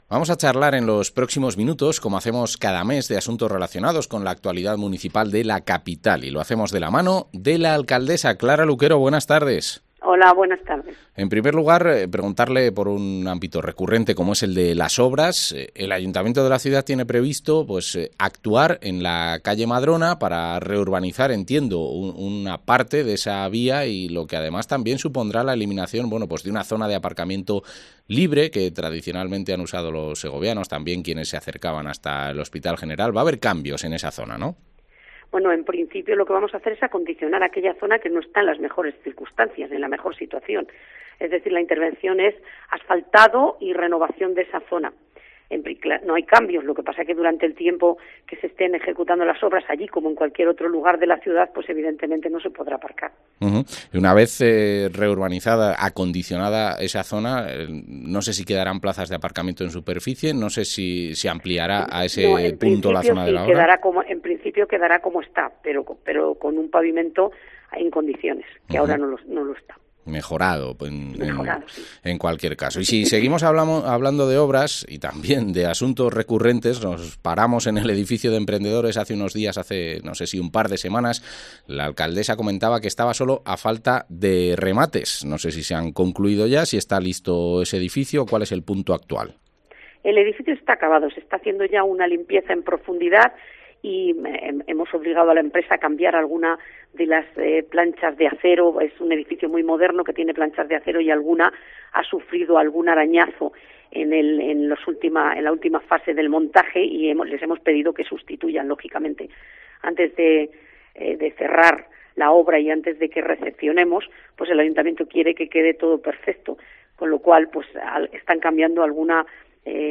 Entrevista a la alcaldesa de Segovia, Clara Luquero